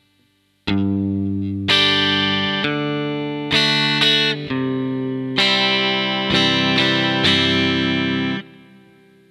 This time, rather than strumming the full G and C chords after their root notes, we strum just part of the chord instead.
This more selective approach creates the sound of two distinct parts – a bassline (root notes) and chords (but only in the higher pitches).